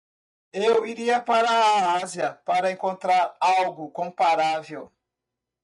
Pronounced as (IPA)
/kõ.paˈɾa.vew/